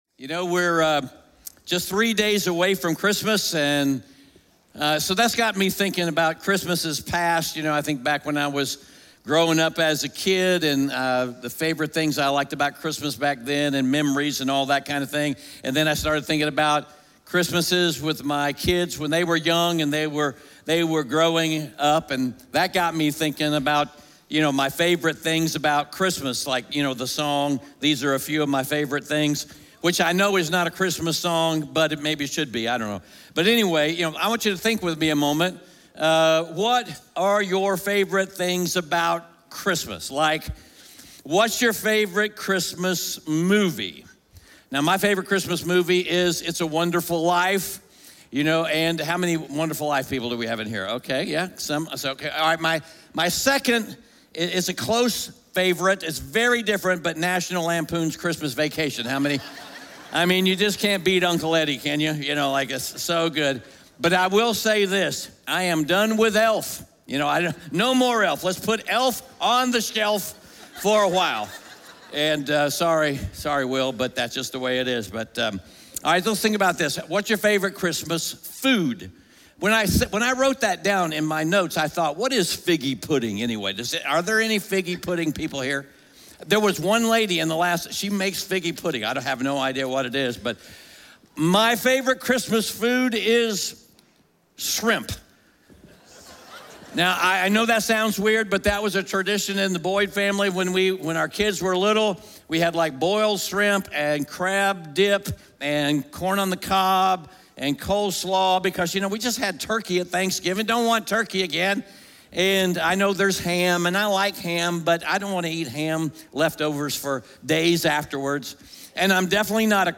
Galatians 4:4-7 Audio Sermon Notes (PDF) Ask a Question SERMON SUMMARY God incarnated Himself in Jesus so that Jesus would be incarnated in us.